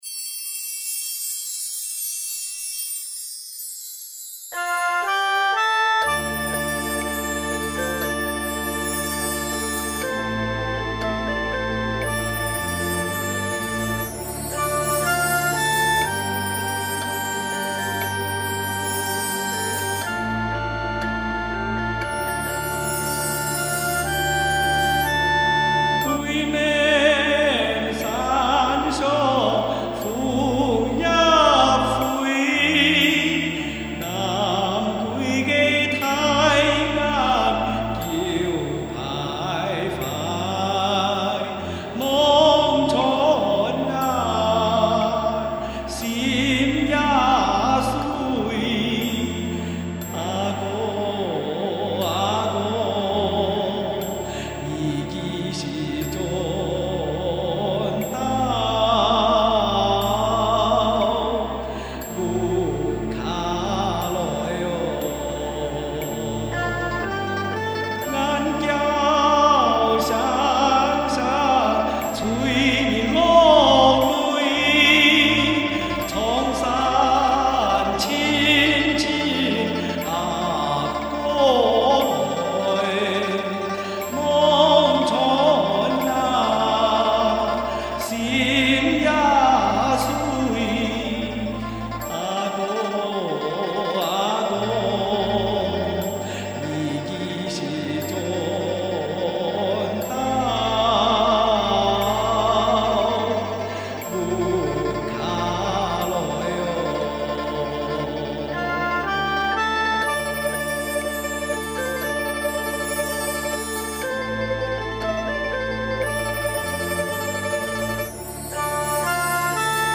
客家歌《秋思》